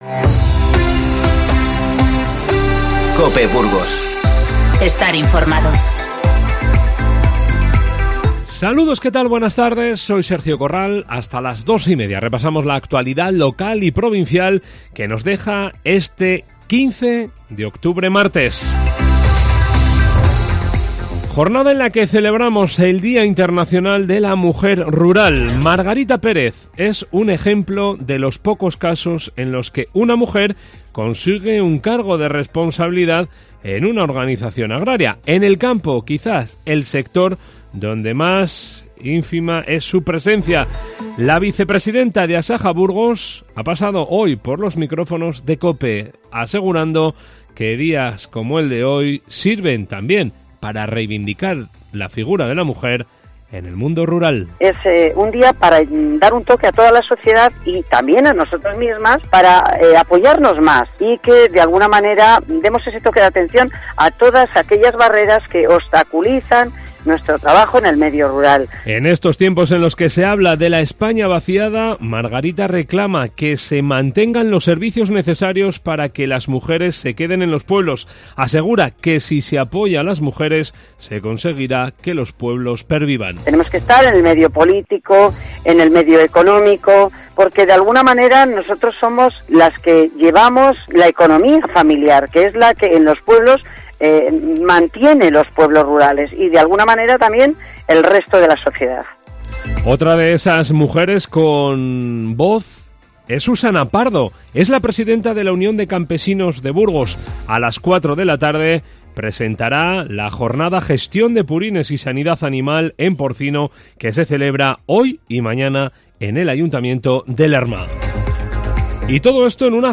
INFORMATIVO Mediodía 15-10-19